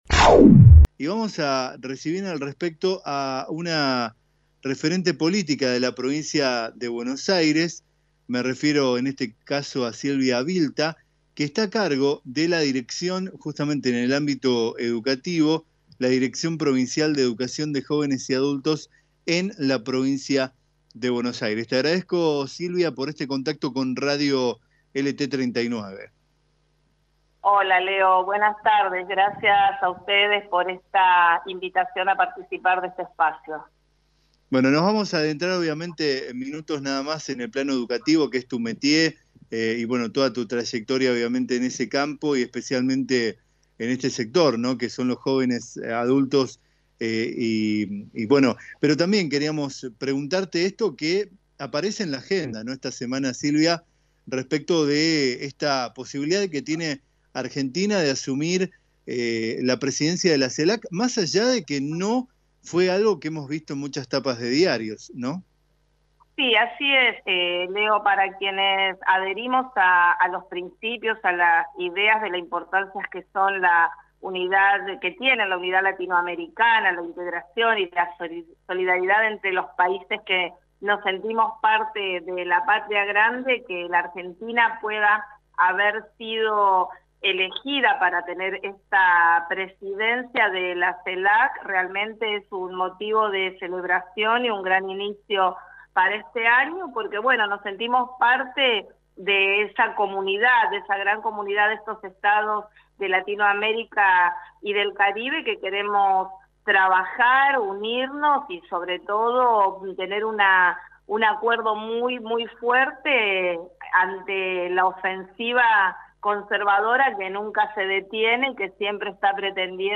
Silvia Vilta, Directora Provincial de Jóvenes y Adultos de la provincia de Buenos Aires sostuvo en diálogo con LT 39 AM 980, que la elección de Argentina a la presidencia de la CELAC es un motivo de celebración.